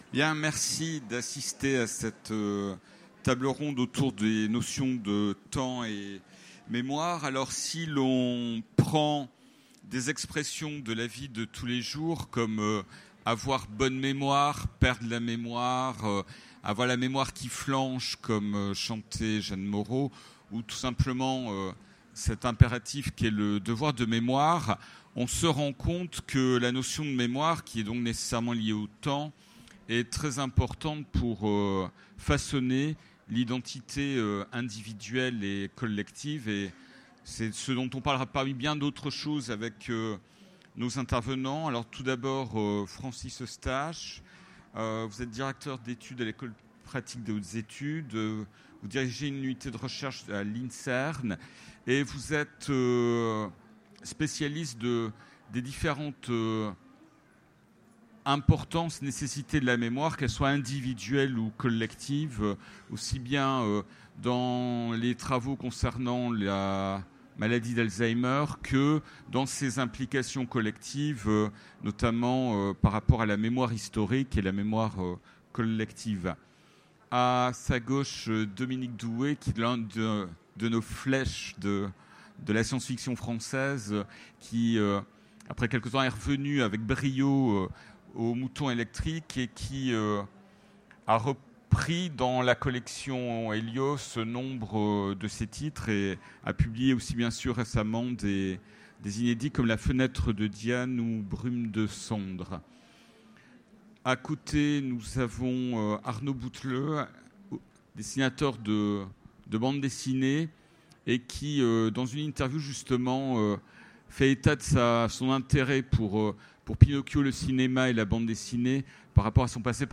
Utopiales 2017 : Conférence Temps et mémoire